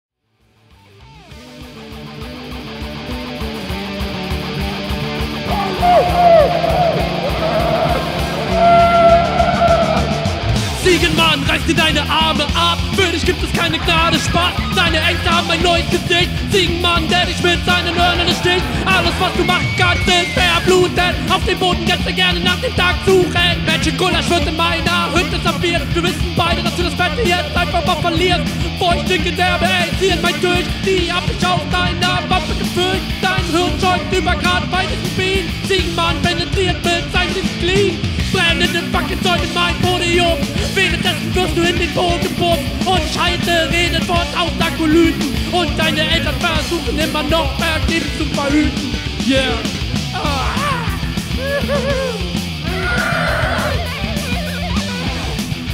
Aber klingt eher wie Punk, Rock, die Richtung.